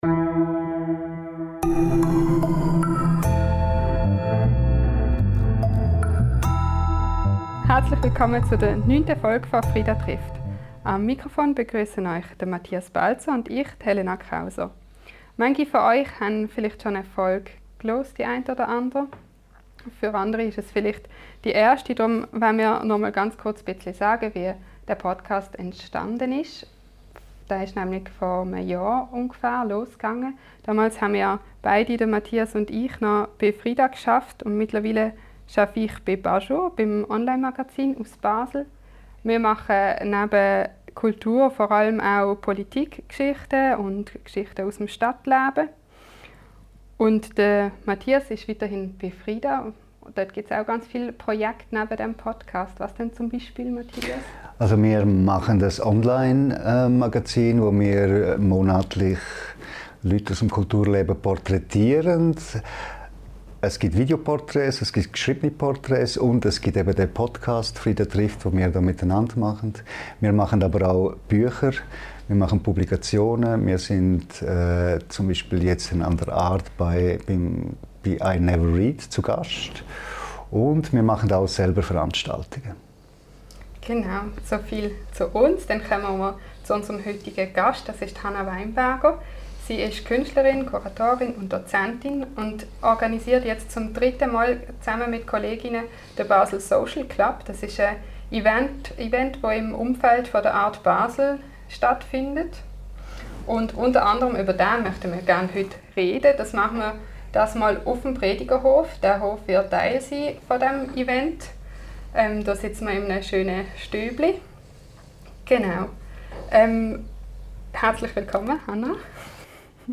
In der weiten Landschaft findet während der ART der dritte Basel Social Club statt. Das mittlerweile riesige Kunst-Happening schafft, was die Messe nicht (mehr) schafft: Kunst und Menschen unterschiedlichster Herkunft auf Augenhöhe zusammenbringen. Ein Gespräch über Kunst, Kommerz und schlaflose Nächte.